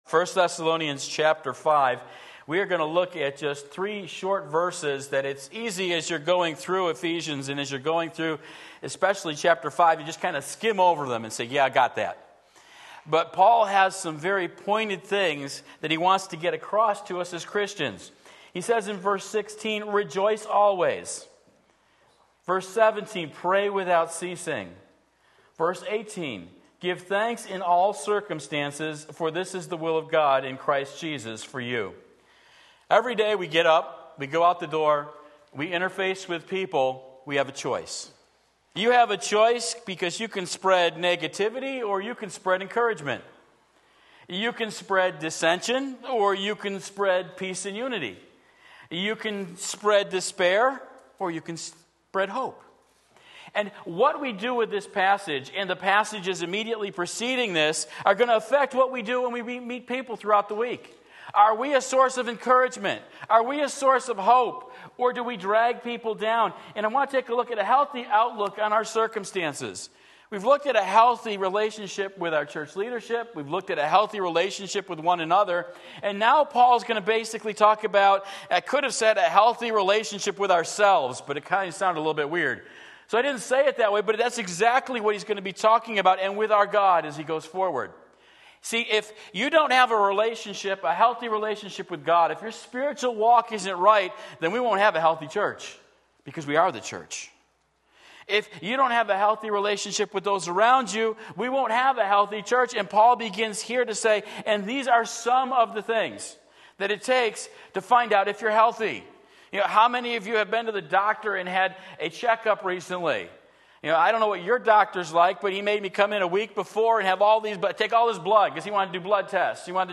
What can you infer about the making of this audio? A Healthy Outlook on Our Circumstances 1 Thessalonians 5:16-18 Sunday Morning Service